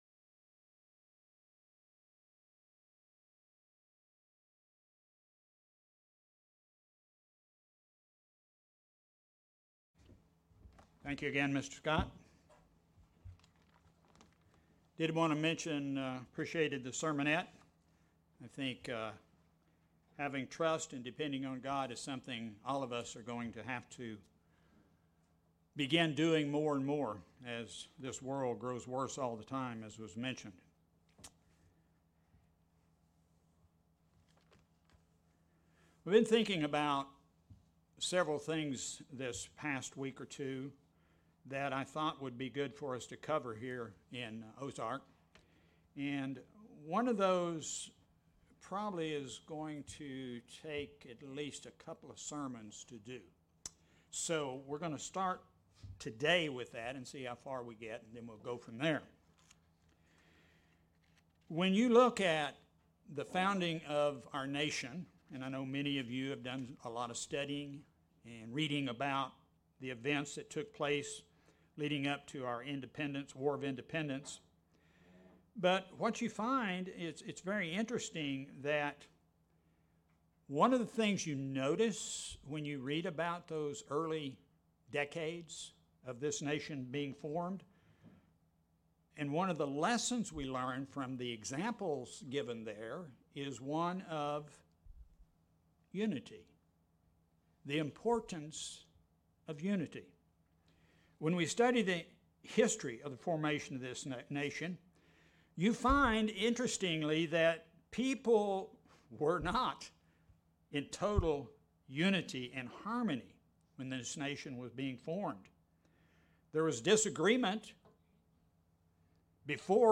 Sermons
Given in Springfield, MO